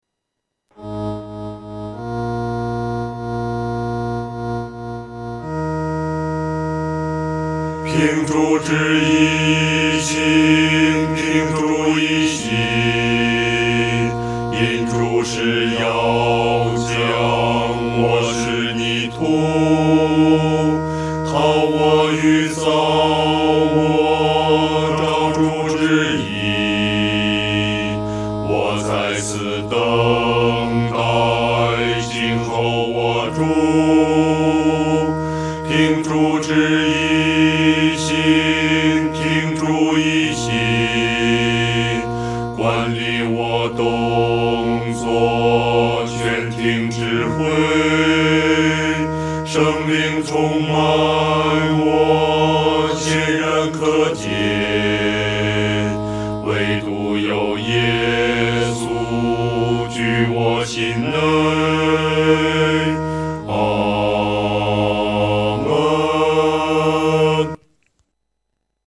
男高